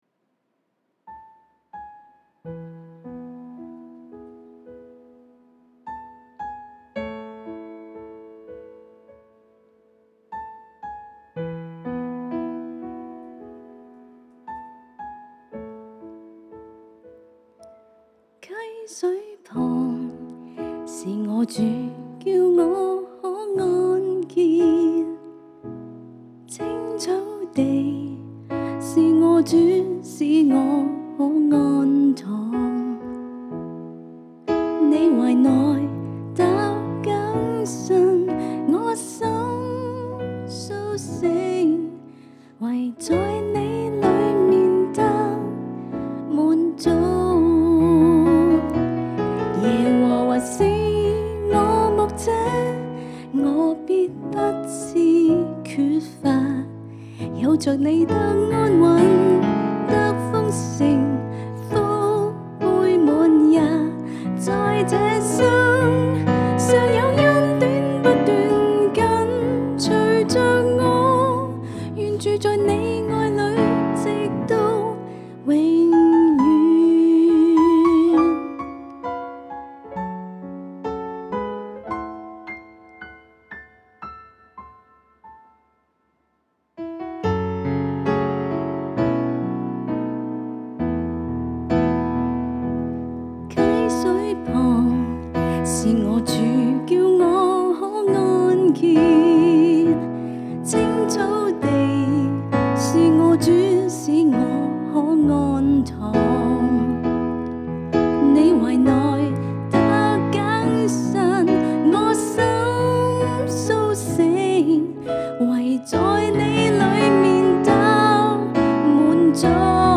現場敬拜